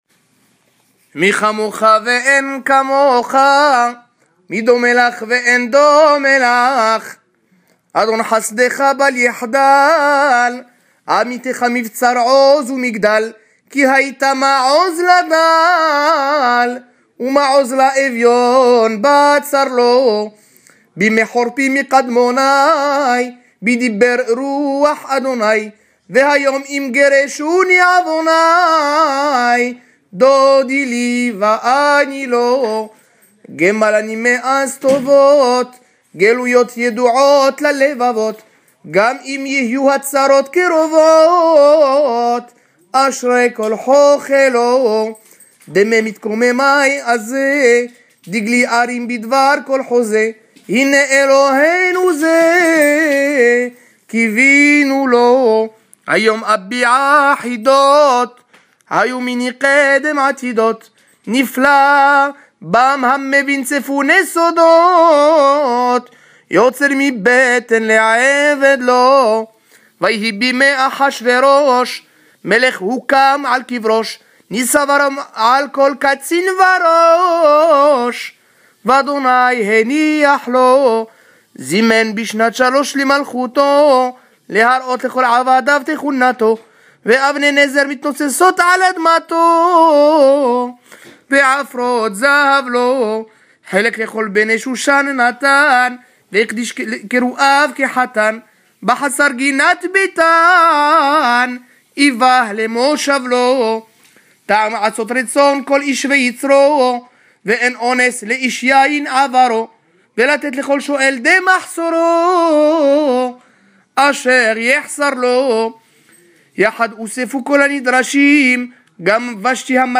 Piyout composé par Rabbi Yéhouda Halévi et qui raconte tout le livre d’Esther.
Hazanout
Lu avant la sortie de la Torah à l’occasion du Shabbath Zakhor Selon l’air traditionnel Marocain